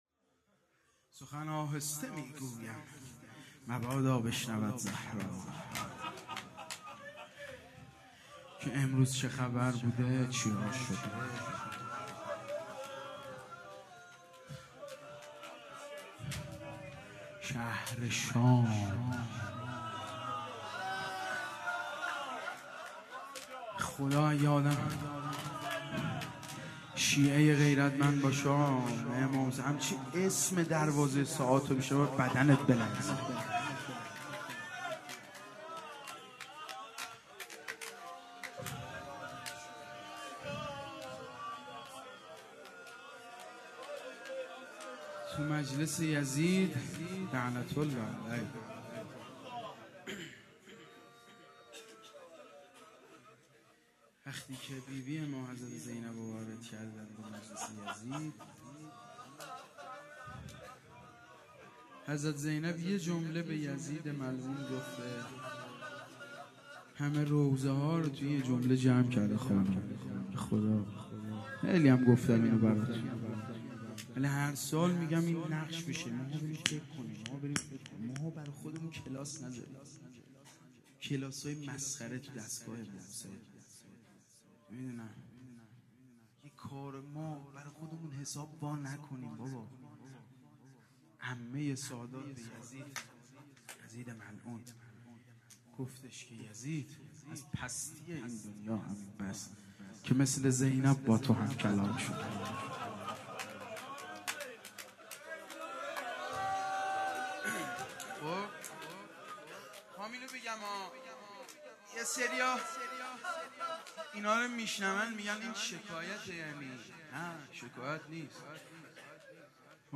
06-Rozeh.mp3